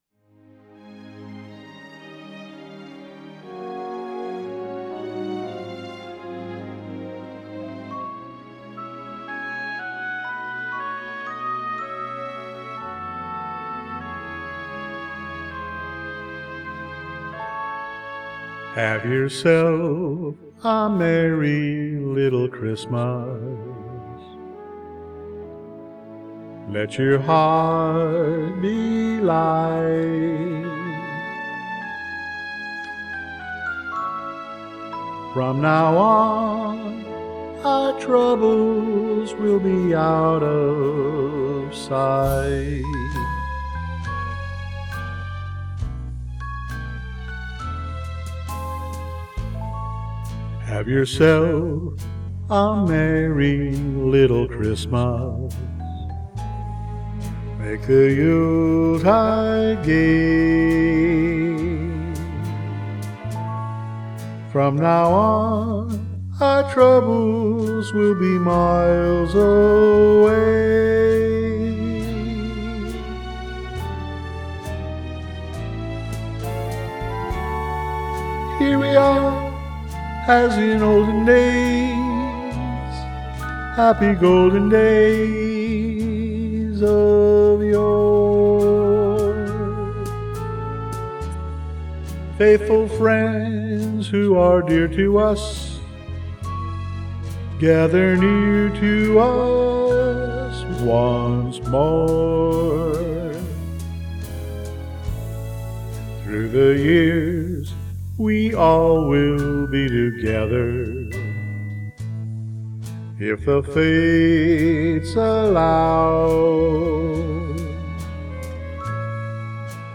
My Christmas Music covers